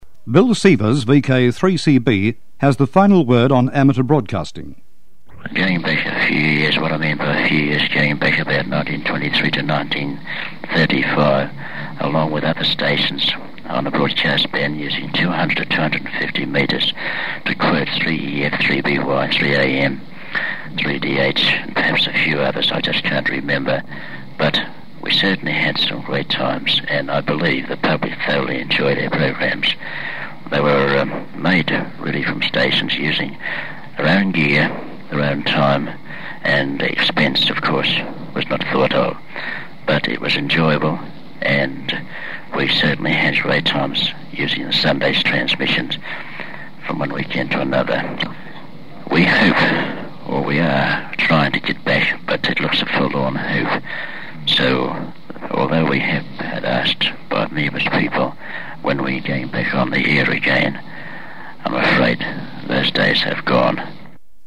This recording was made around 1950 on a paper based magnetic disc - a little like the "floppy discs" used in early computers. In this case, an early English "Recordon" office dictating machine was used.